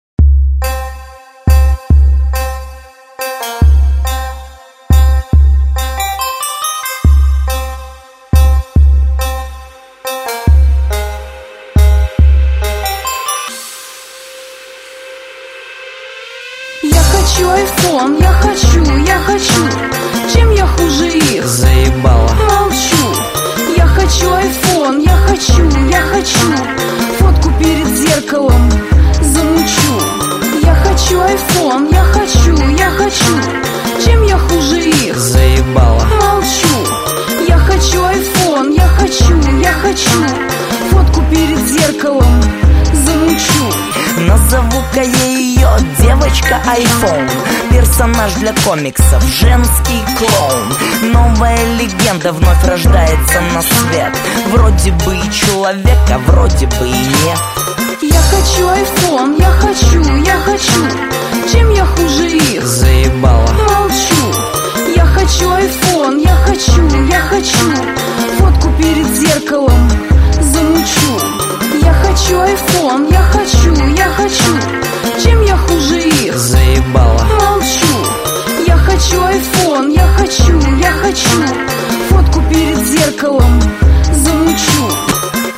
• Качество: 128, Stereo
Присутствует в основном только припев.